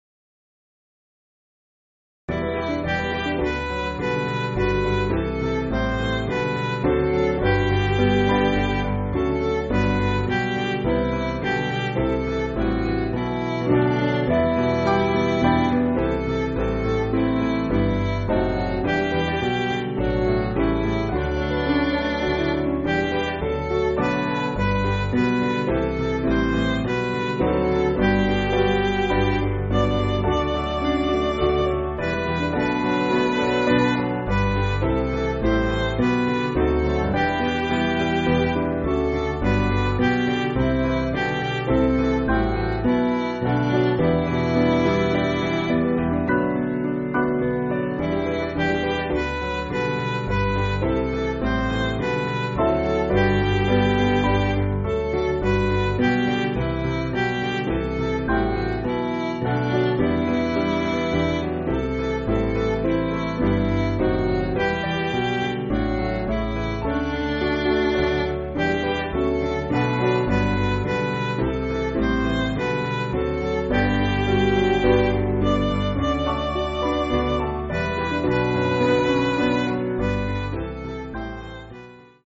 8.8.8.8 with Refrain
Piano & Instrumental
3/Em